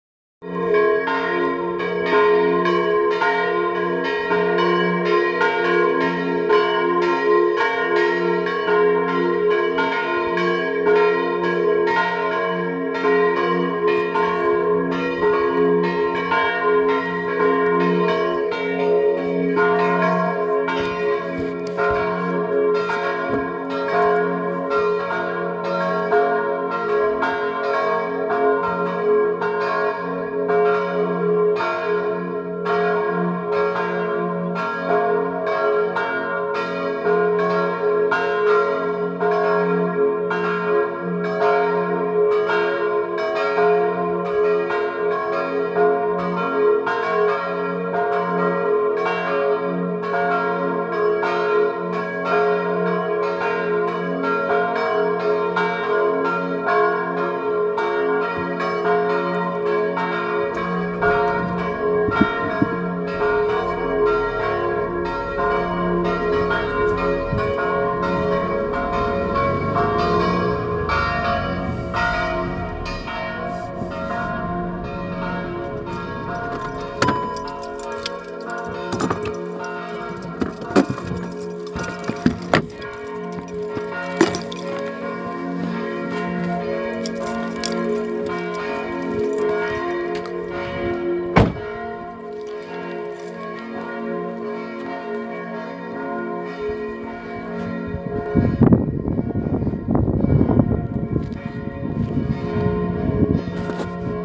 Kirchenglocken Sittersdorf.mp3